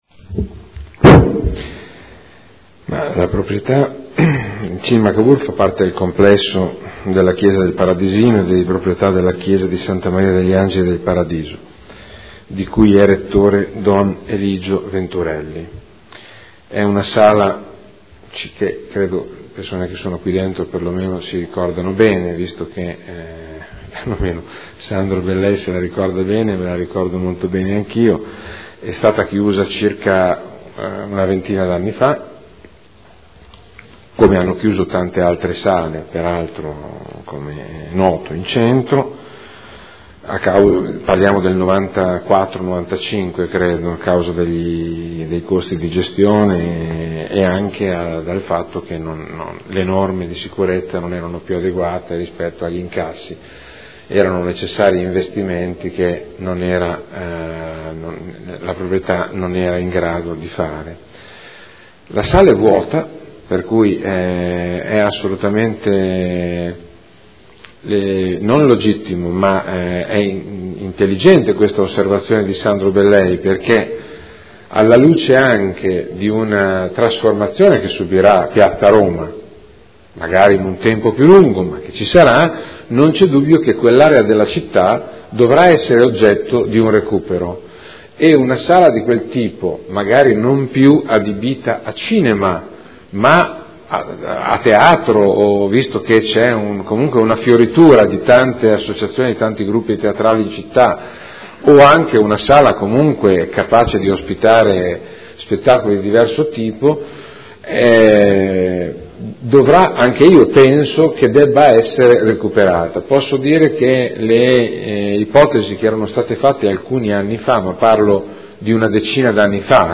Seduta del 6 marzo.